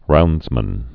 (roundzmən)